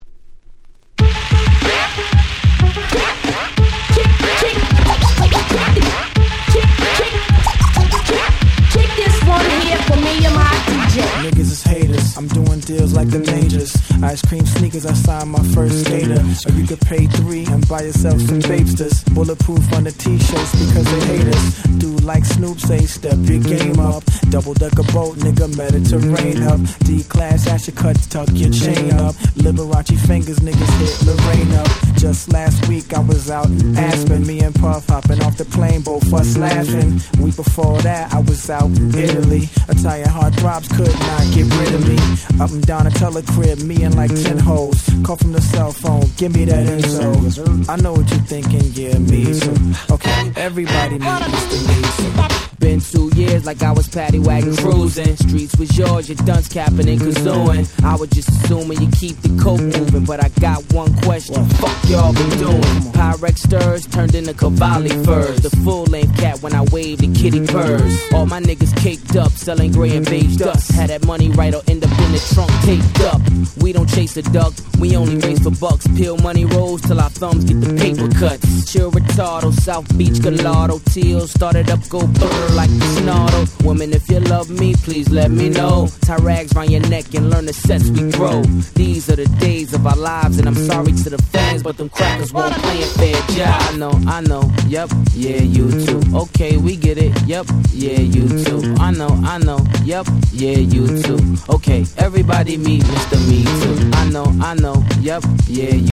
06' Very Nice R&B Remix !!